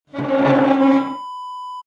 Chair scraping floor sound effect .wav #3
Description: The sound of a chair scraping on the floor
Properties: 48.000 kHz 16-bit Stereo
A beep sound is embedded in the audio preview file but it is not present in the high resolution downloadable wav file.
Keywords: chair, scrape, scraping, screech, screeching, move, moving, push, pushing, pull, pulling, drag, dragging, hardwood, wooden, floor
chair-scraping-floor-preview-3.mp3